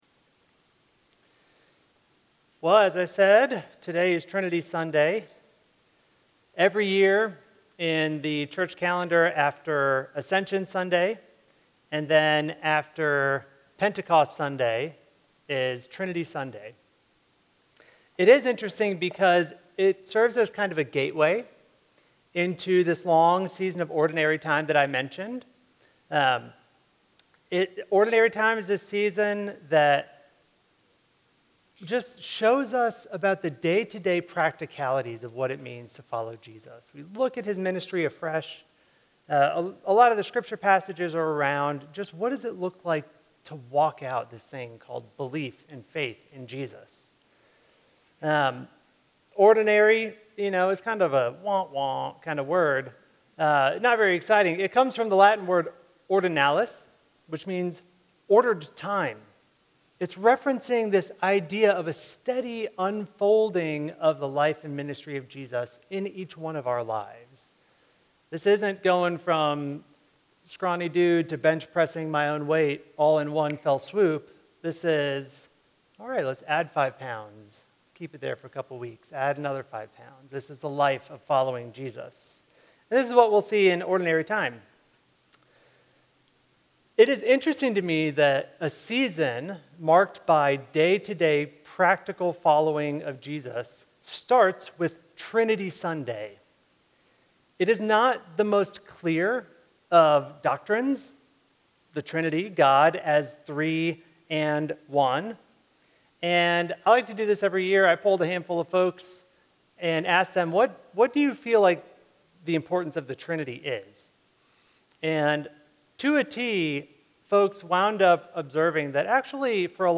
Trinity Sunday